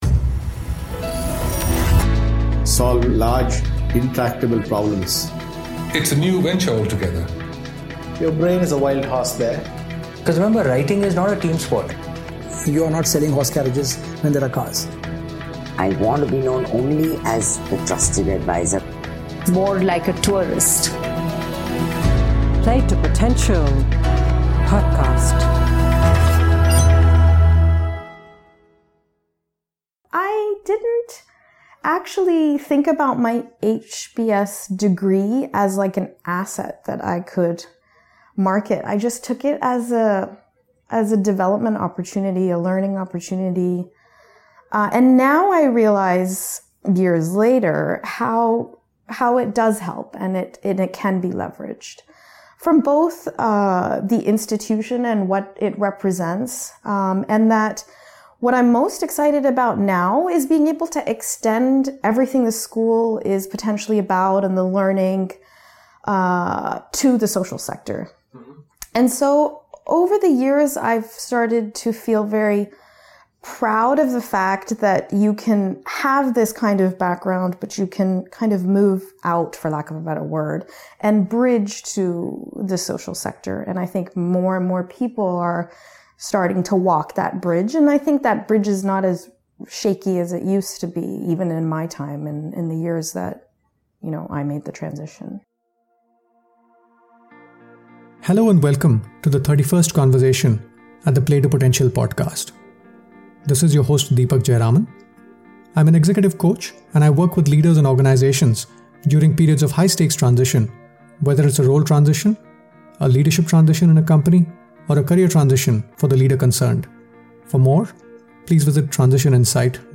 ABOUT THE PODCAST Play to Potential podcast started in Dec 2016 and features conversations around three broad themes - Leadership, Transitions and Careers.